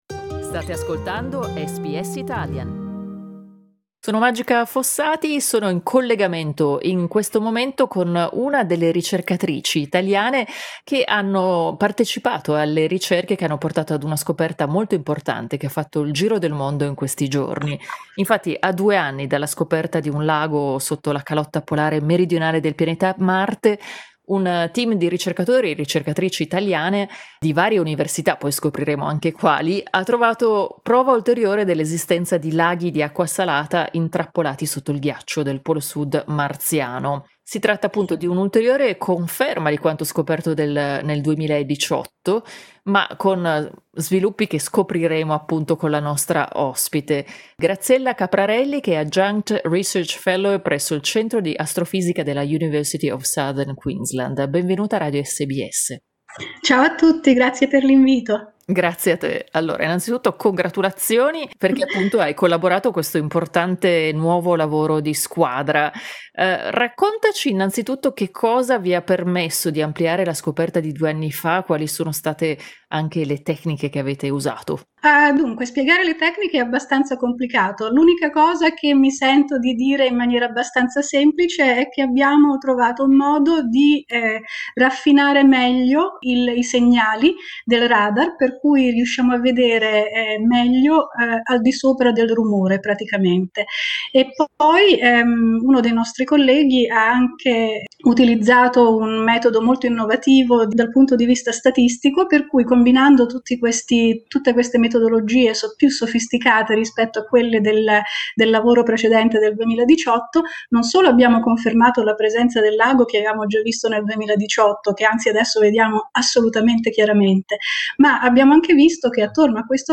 Al microfono di SBS Italian ha spiegato cosa è stato trovato e in che modo.